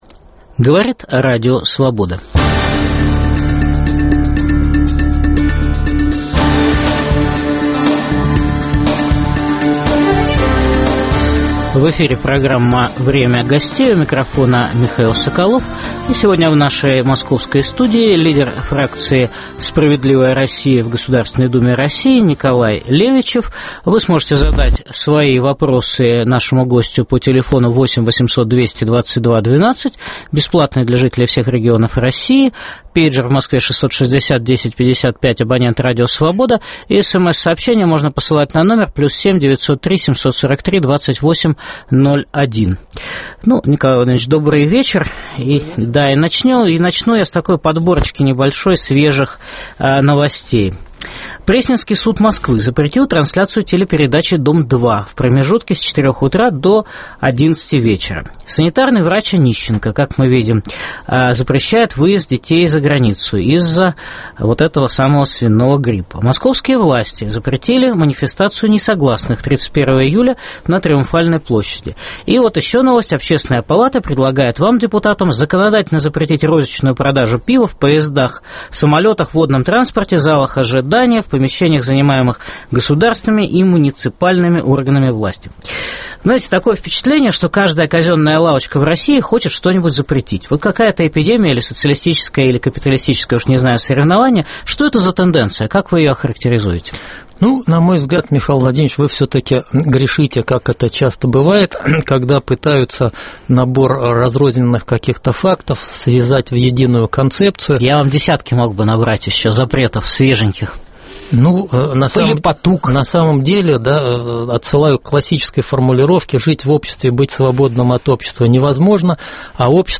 В программе выступит лидер фракции "Справедливая Россия" в Государственной Думе РФ Николай Левичев.